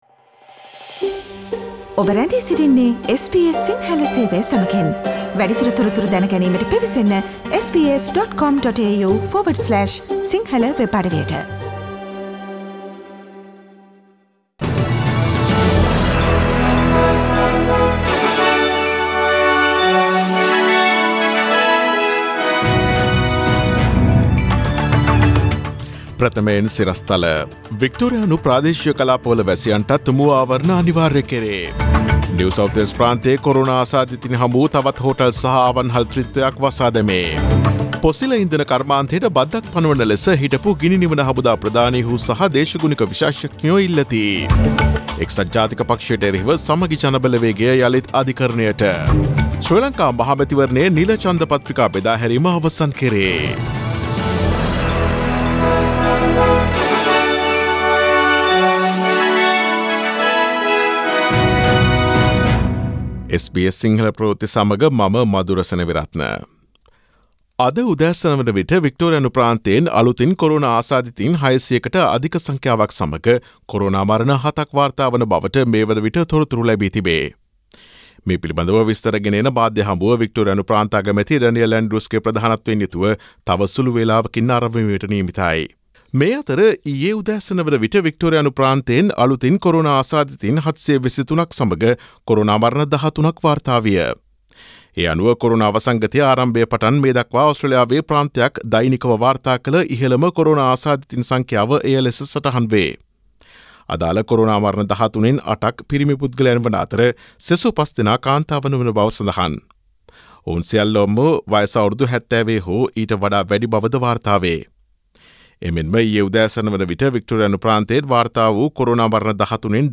Daily News bulletin of SBS Sinhala Service: Friday 31 July 2020
Today’s news bulletin of SBS Sinhala Radio – Friday 31 July 2020 Listen to SBS Sinhala Radio on Monday, Tuesday, Thursday and Friday between 11 am to 12 noon